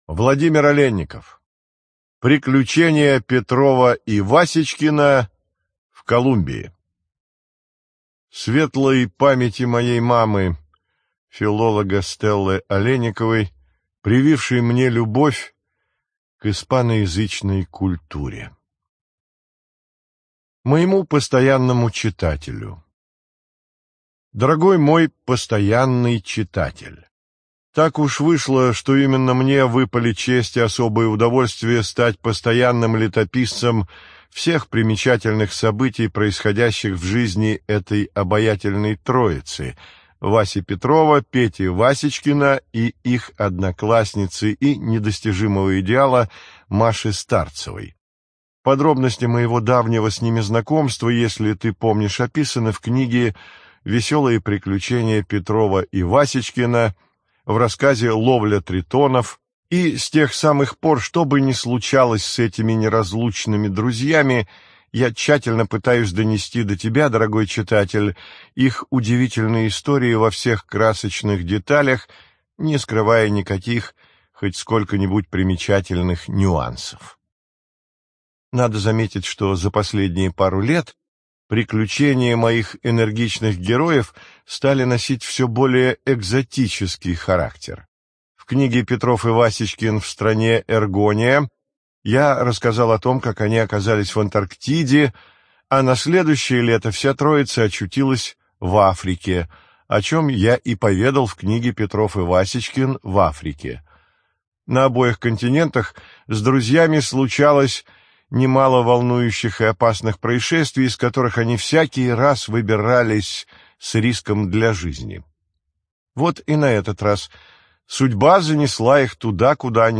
ЖанрПриключения, Путешествия